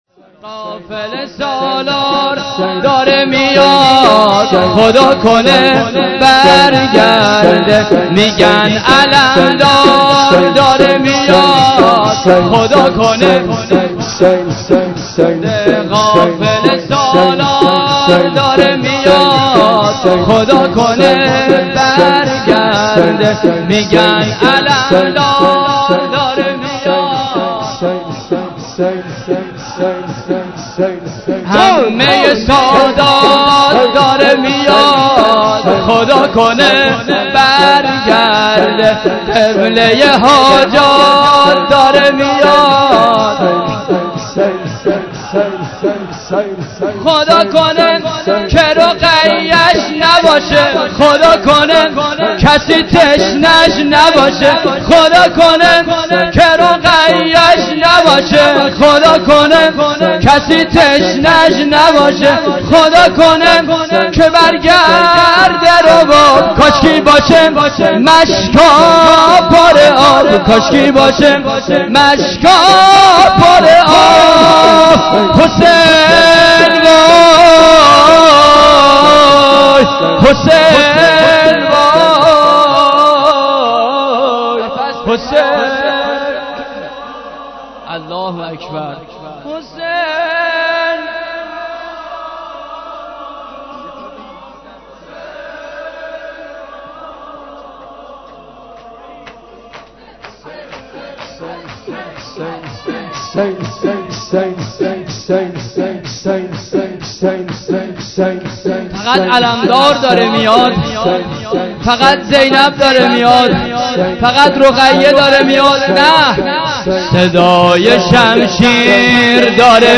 شور شب دوم محرم 93